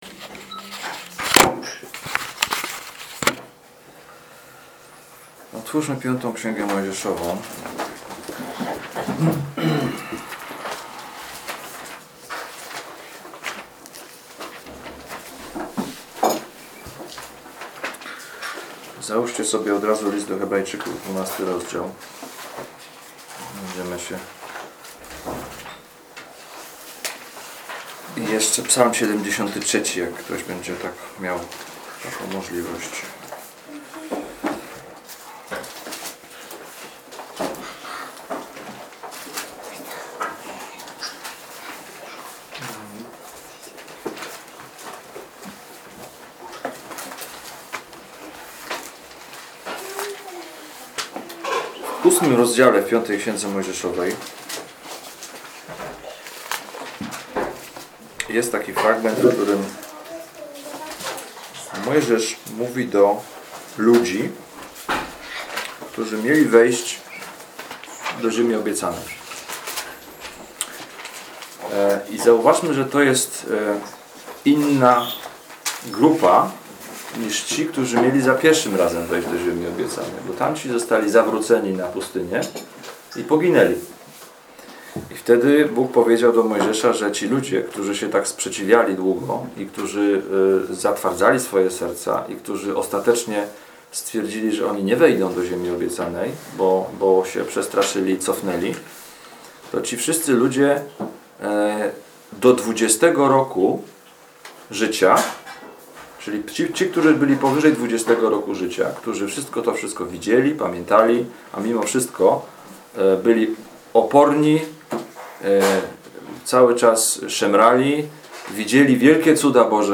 Ulica Prosta - Kazania z 2015